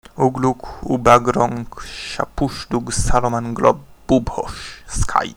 I am aware of the pronunciation mistakes - I done those files long ago, when my knowledge was very limited.